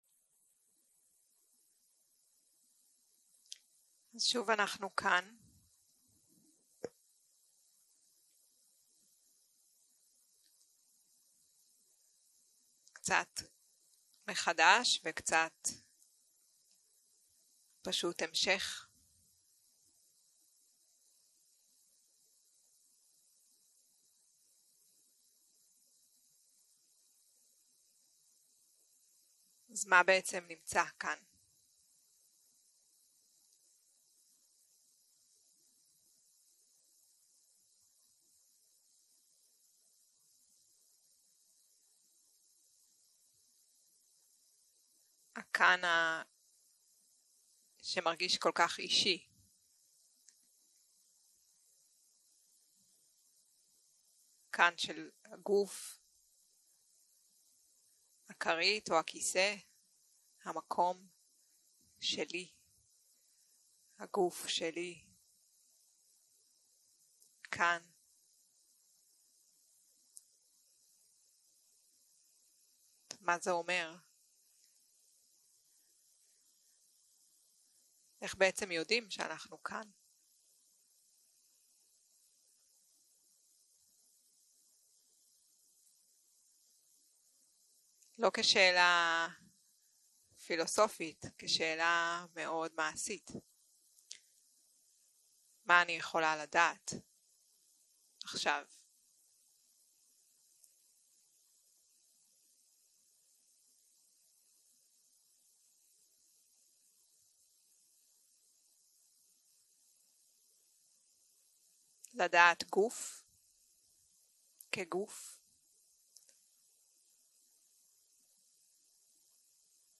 יום 6 - הקלטה 16 - צהרים - מדיטציה מונחית